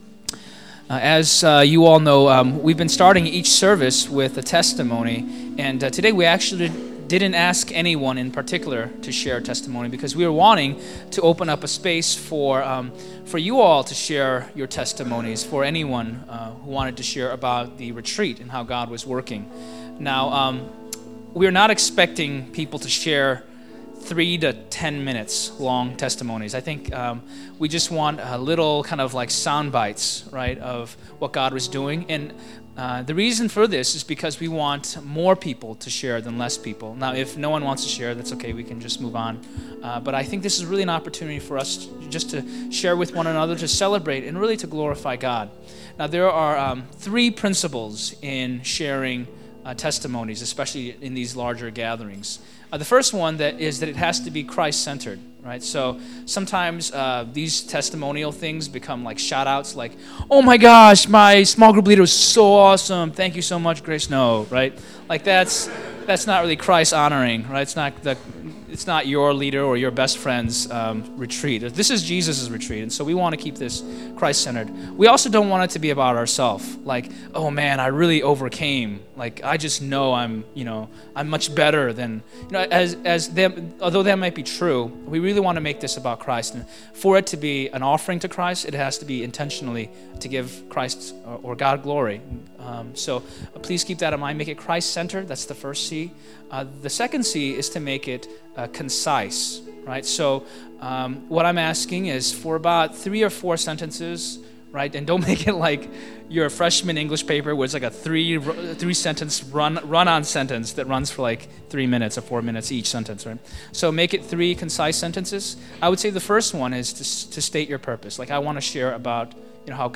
Congregational Retreat 2016: First Love Service Type: Sunday Celebration « Congregational Retreat 2016